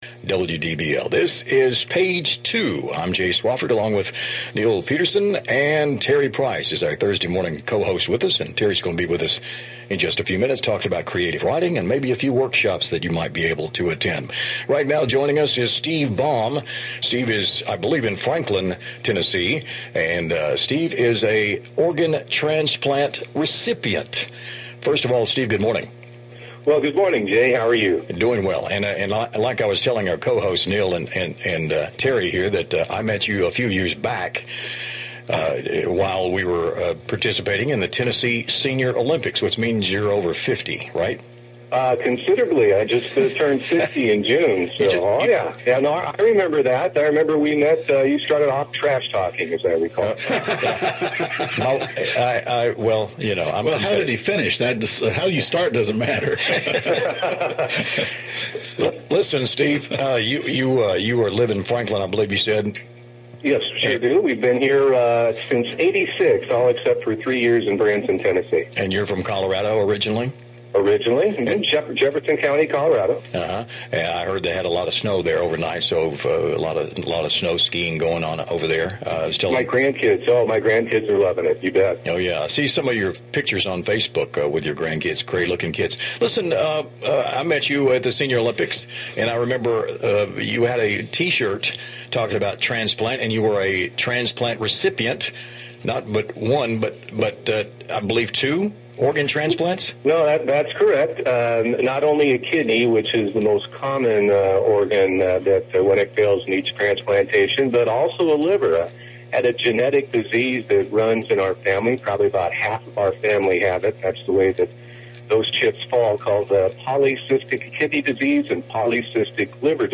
A Radio Interview in Nashville on Organ Donation
Loose and funny…you can tell we’re friends.